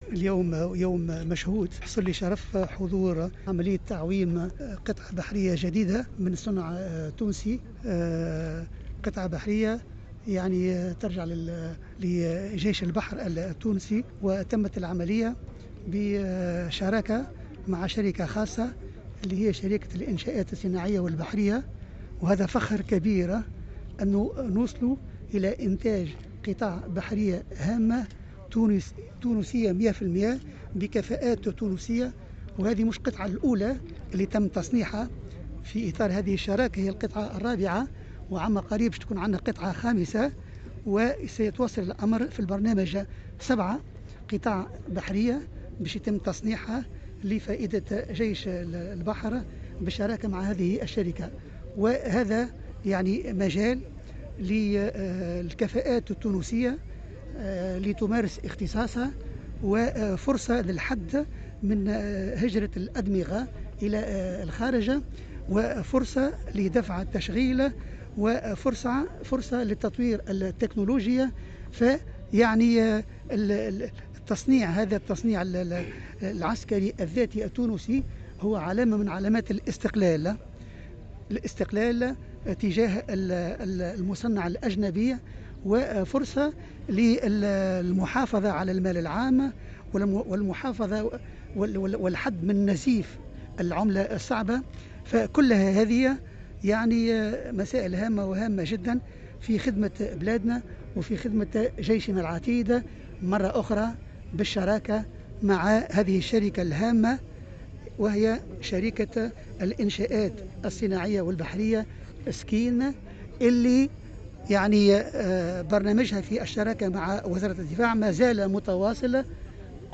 ووصف البرتاجي في تصريح للجوهرة أف أم هذا الحدث بـ"اليوم المشهود وبالفخر الكبير"، مؤكدا أهمية هذه الإنجازات في تعزيز امكانيات جيش البحر في منظومة المراقبة وحماية الحدود البحرية ومقاومة الهجرة غير الشرعية.